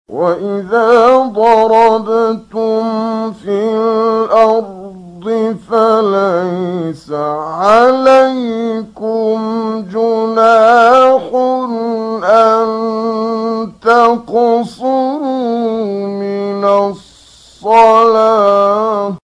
15 فراز از «کامل یوسف» در مقام بیات
تلاوت در کانال‌های قرآنی/
گروه شبکه اجتماعی: فرازهای صوتی از کامل یوسف البهتیمی که در مقام بیات اجرا شده است، می‌شنوید.